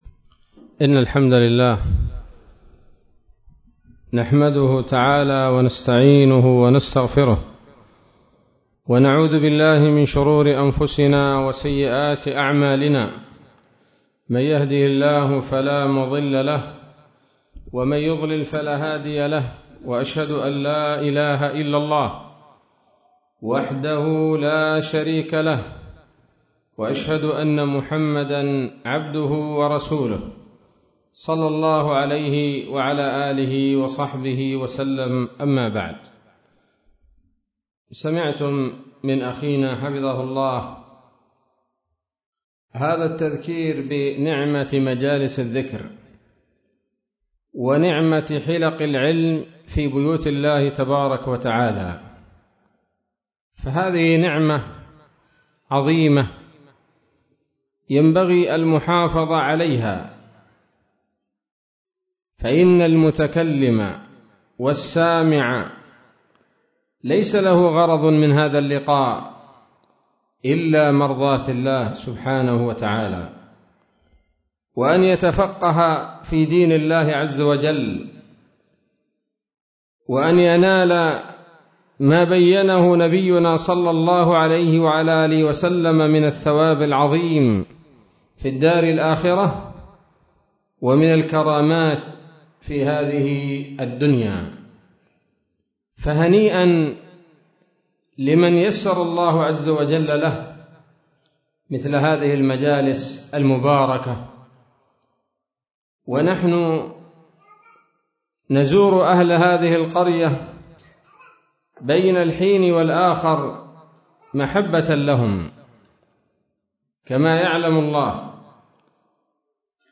محاضرة قيمة بعنوان: (( ‌التوحيد والصلاة )) ليلة السبت 27 شوال 1443هـ، بمنطقة قعوة - الصبيحة - لحج - اليمن